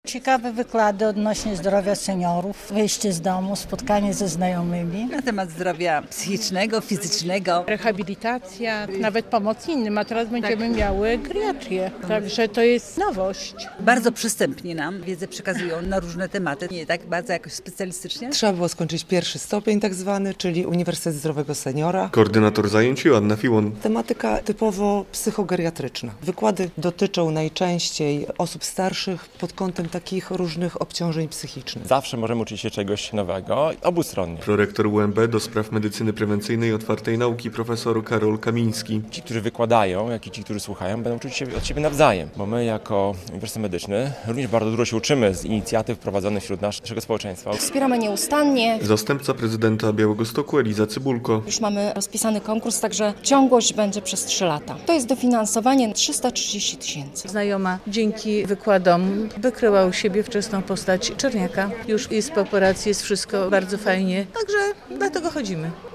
Białostoccy seniorzy kontynuują naukę na Uniwersytecie Zdrowego Seniora - relacja